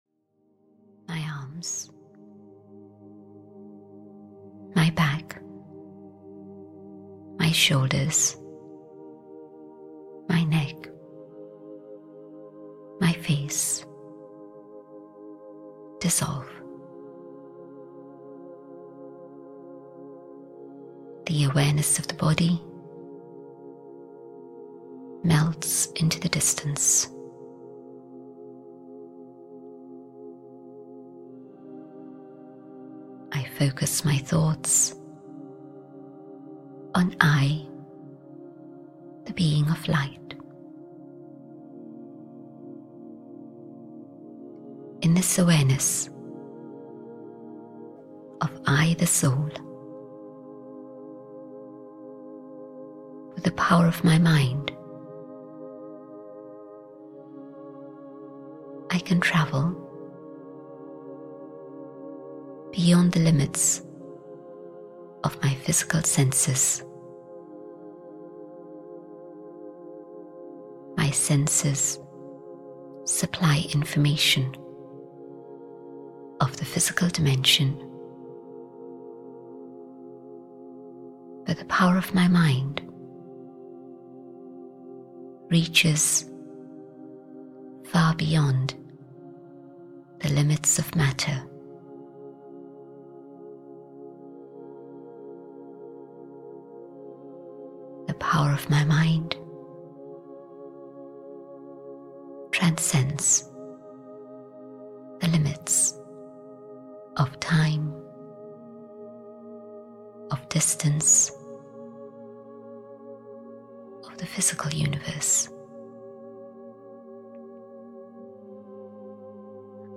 Ukázka z knihy
The Brahma Kumaris World Spiritual University presents "Knowing God", a calming, guided-meditation that will help you relax, unwind and find peace and maybe even enlightenment.